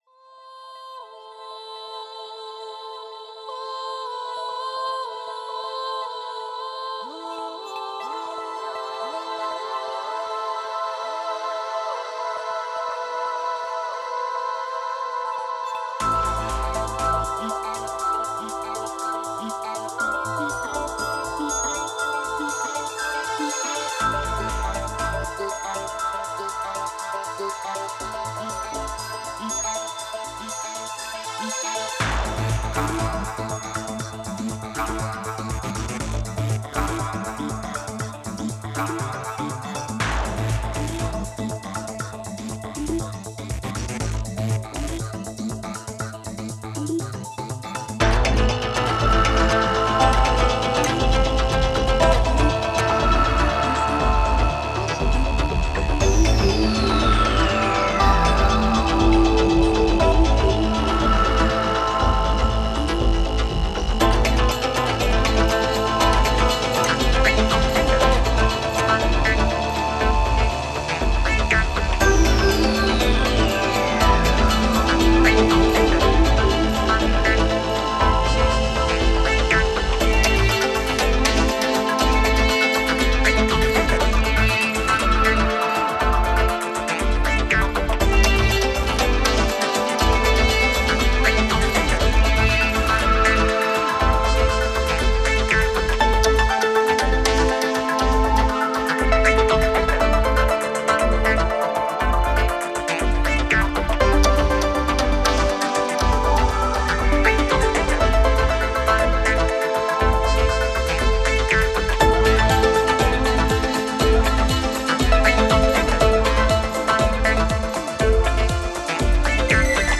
Genre: IDM, Electronic.